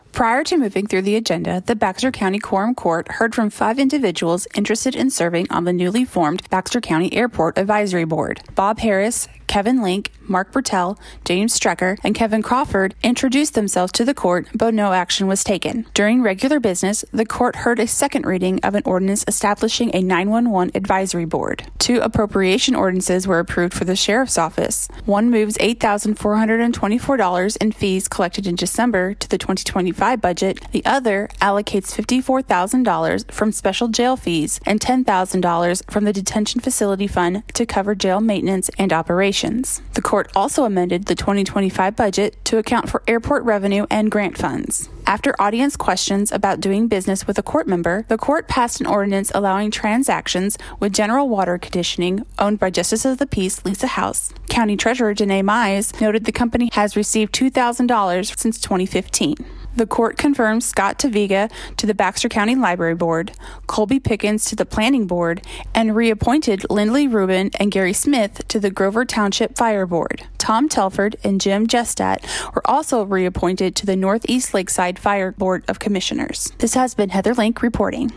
February 5, 2025 5:02 am Local News, WireReady